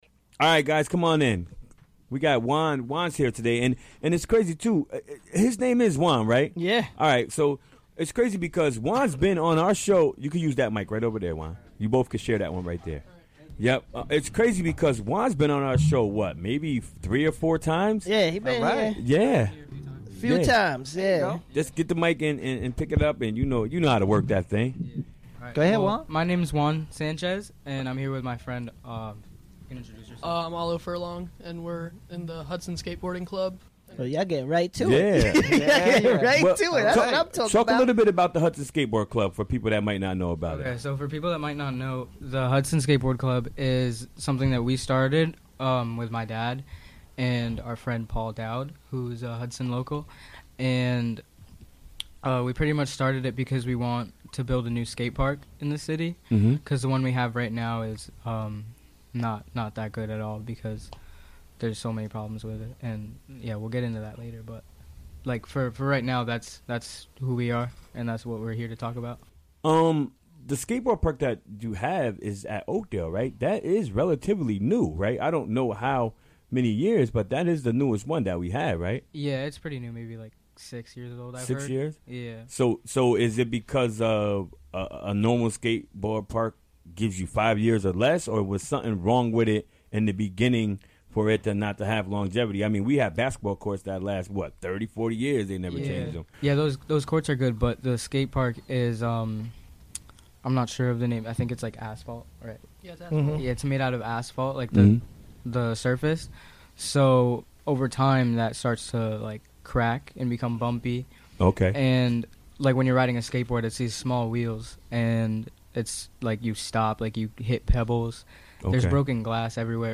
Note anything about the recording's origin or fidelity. Recorded during the WGXC Afternoon Show Wednesday, February 14, 2018.